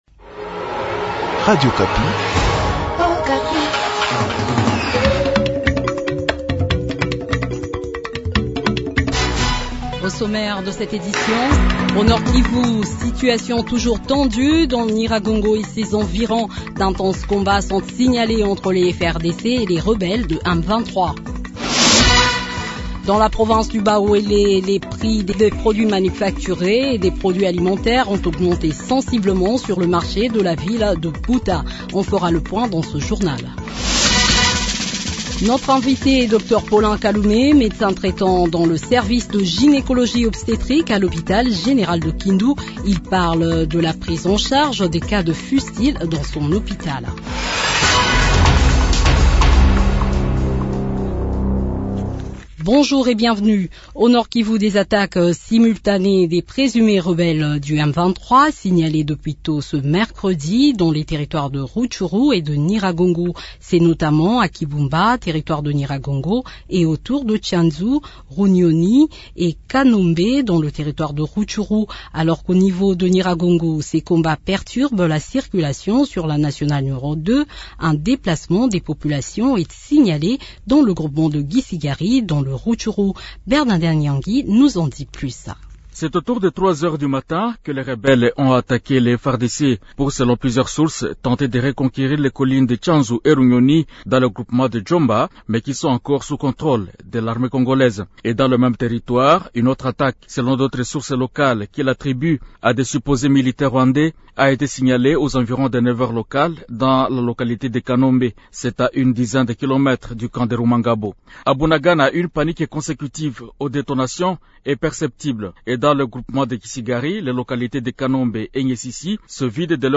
Le journal de 12heures du 25 mai 2022